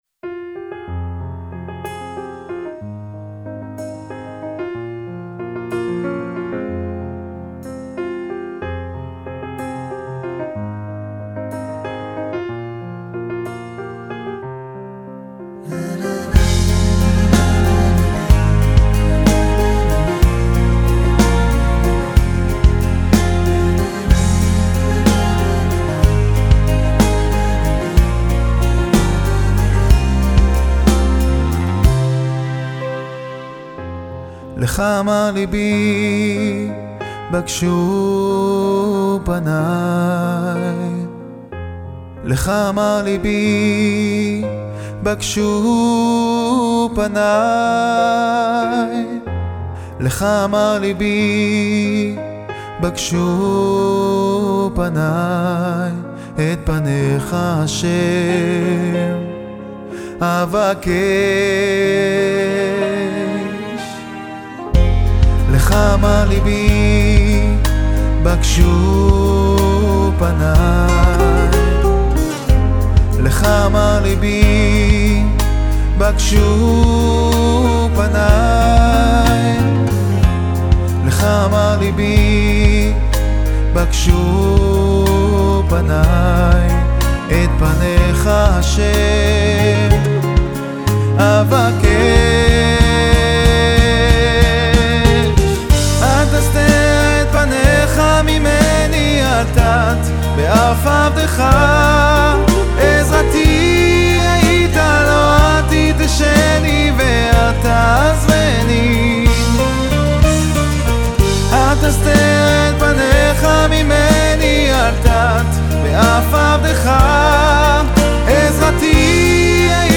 בו תמצאו חוויה מוזיקלית עם צלילים מגוונים ואנרגיות ייחודיות.